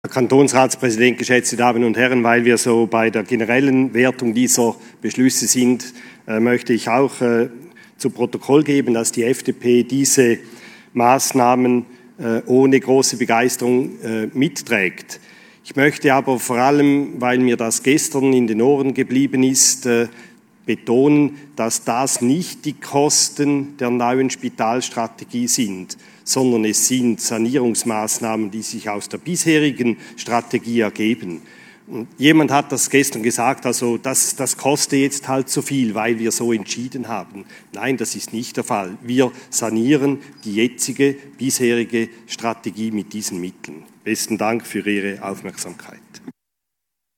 Session des Kantonsrates vom 14. bis 17. September 2020